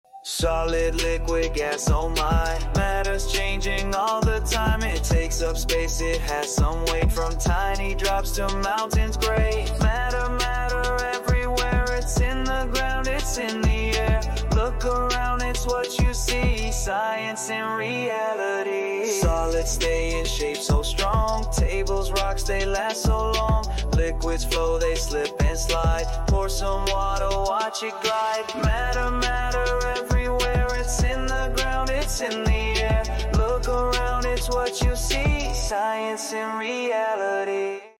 Ice, water, air—we’re rapping through states of matter!